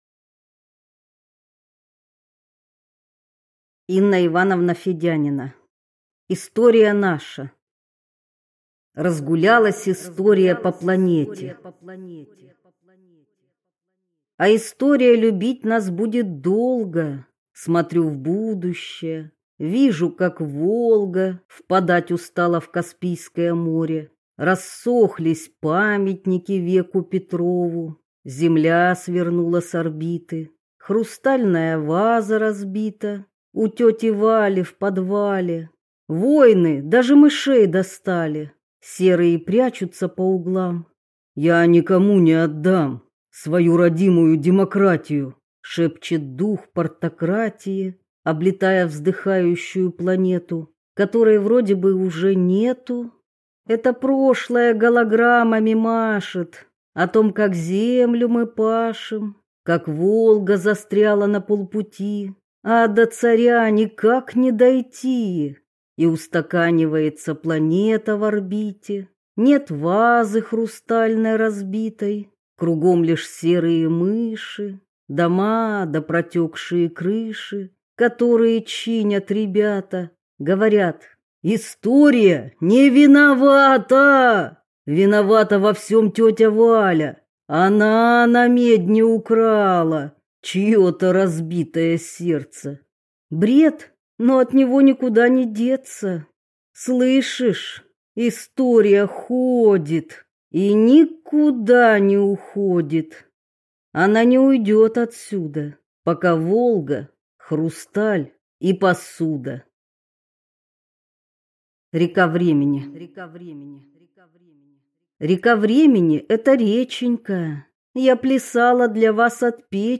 Аудиокнига Стихи: История наша, Будущее, другие Миры | Библиотека аудиокниг